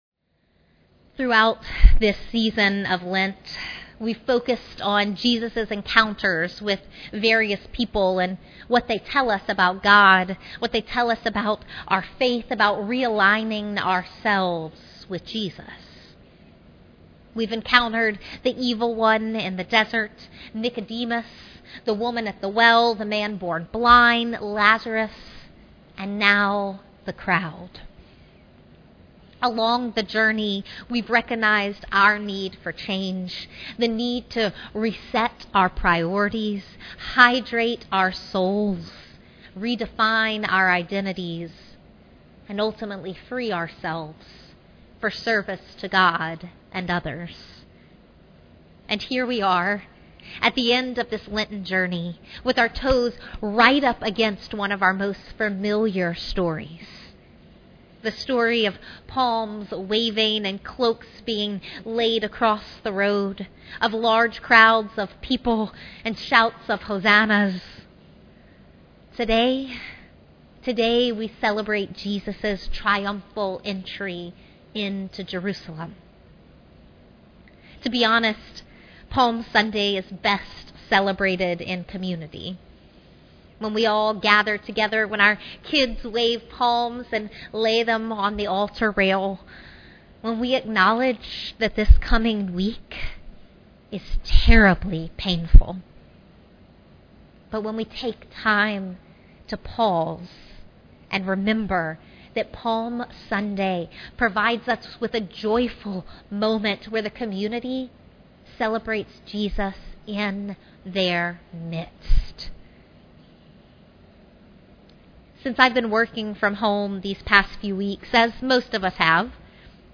We are now offering 2 versions for the sermon, please select the one you like.